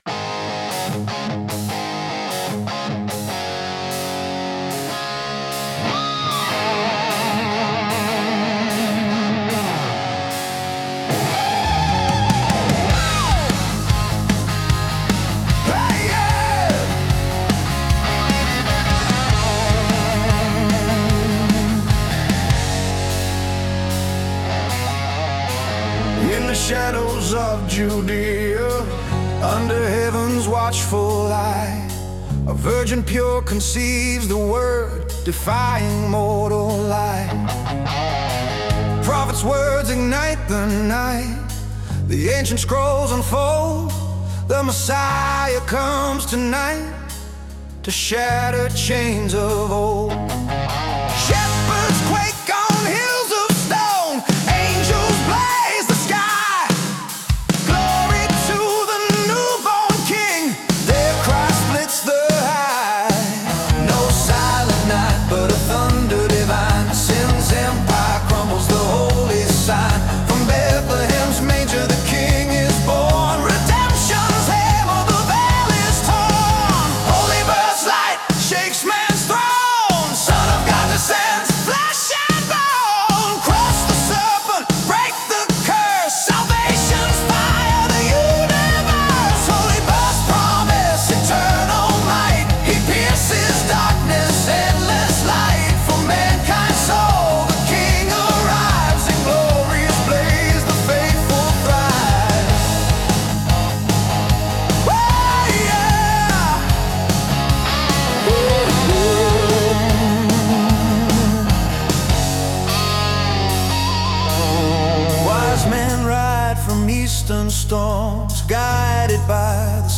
But I got what I wanted out of this song – a badass metal song that is reverential about Christmas.